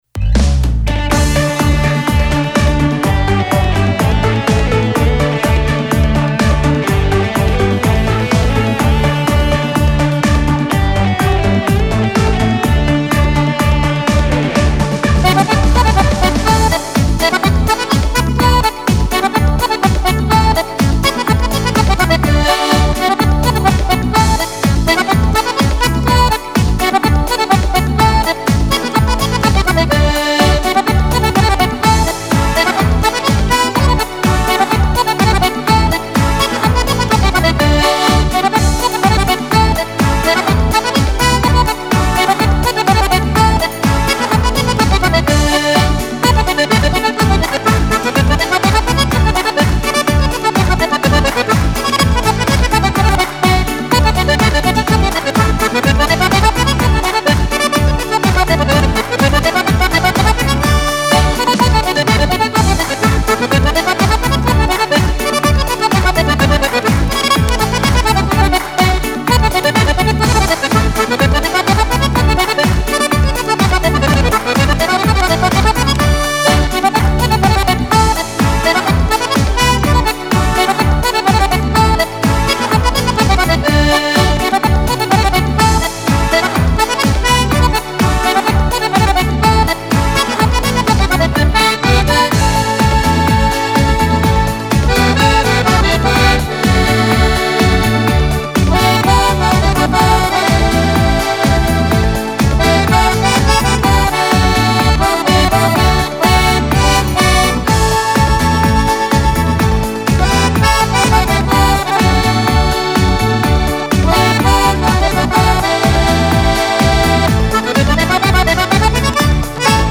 Passeggiata per Organetto